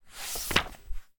Muka Surat Seterusnya.mp3